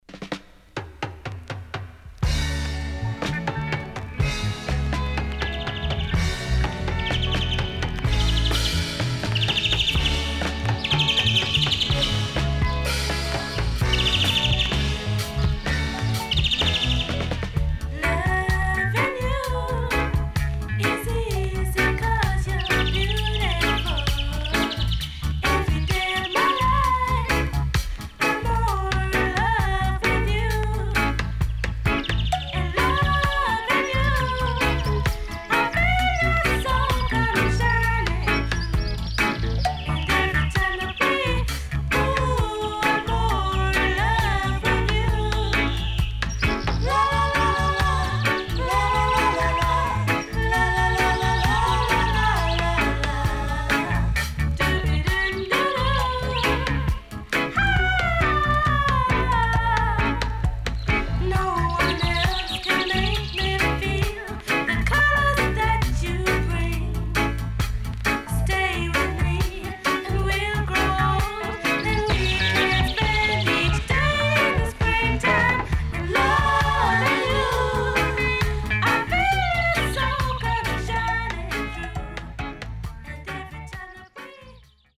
SIDE B:所々チリノイズがあり、少しプチノイズ入ります。